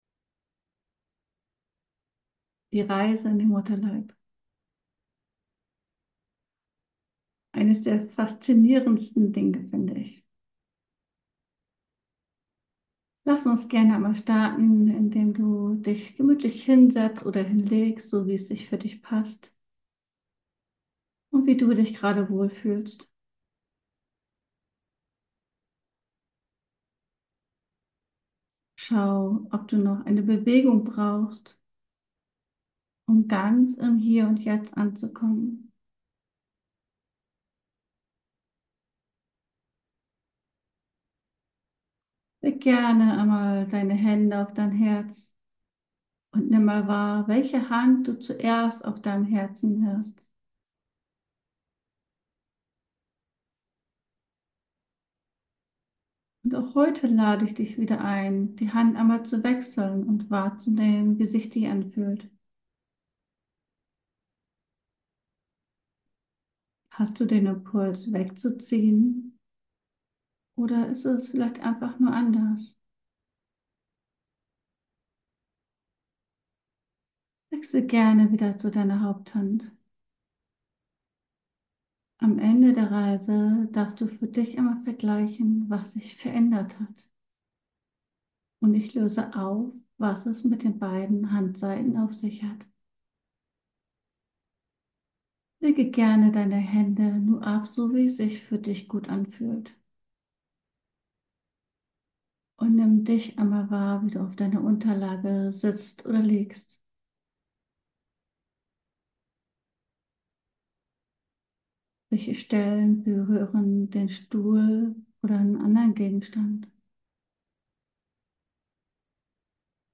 SeelenReise in den Mutterleib – Eine geführte Meditation für Frauen ~ Ankommen lassen Podcast